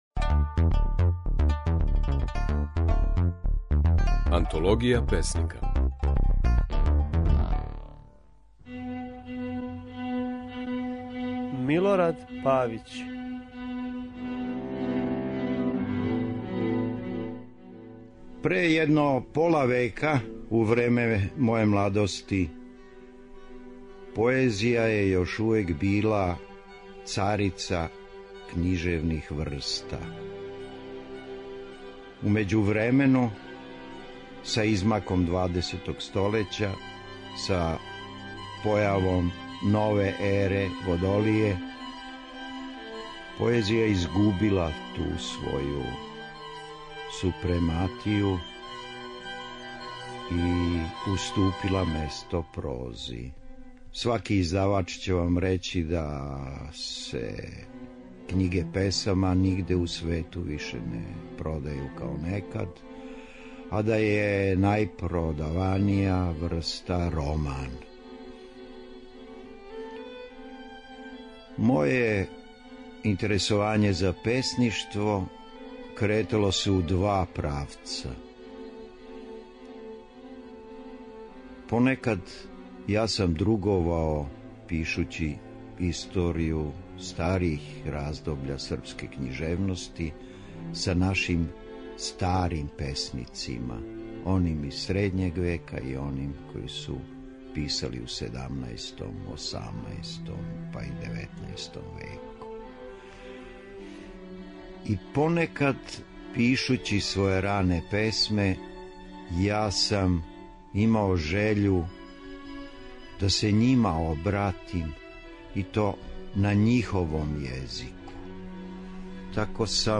Kако је своје стихове говорио Милорад Павић
Можете слушати како је своје стихове говорио романсијер, приповедач, драмски писац, али и песник - Милорад Павић (1929-2009).